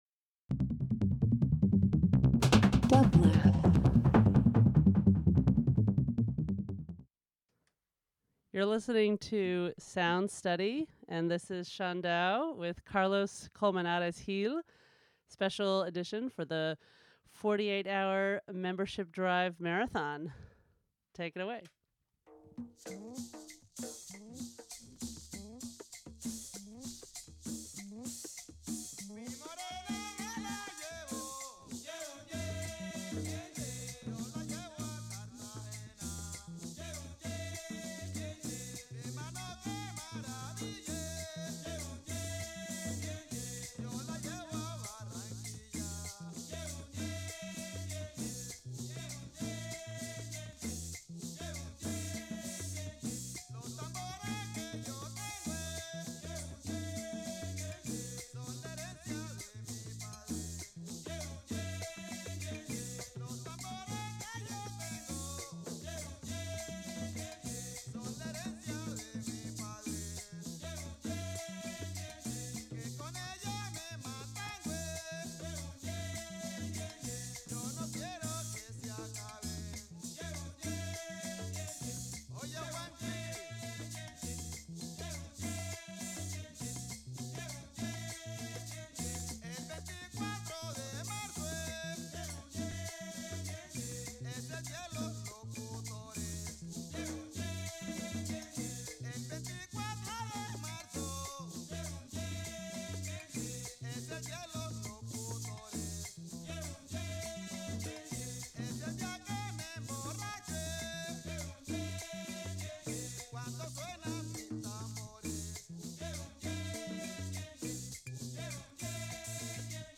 Cumbia International Latin